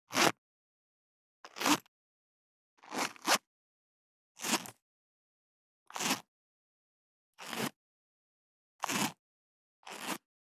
23.ジッパーを開ける【無料効果音】
ASMRジッパー効果音
ASMR